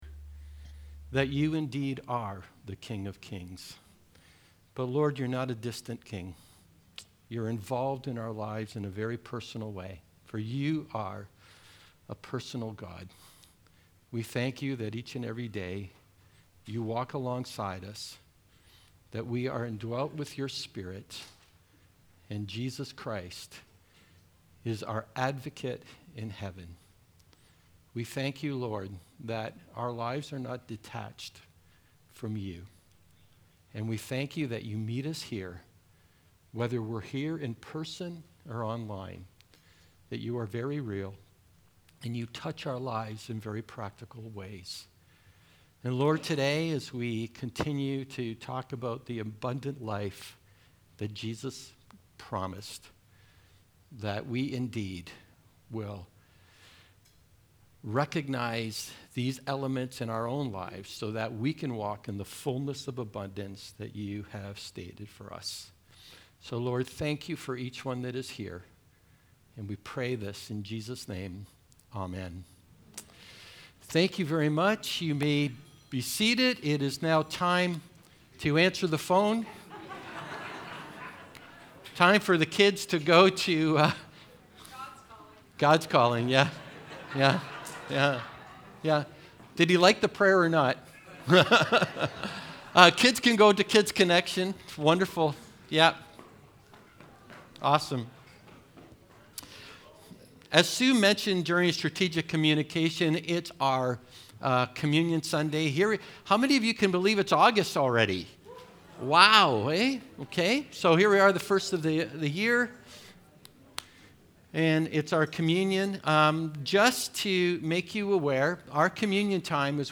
As a part of our sermon series on developing an abundant life through Jesus, this sermon is on the topic of learning to grow spiritually by listening to the Holy Spirit.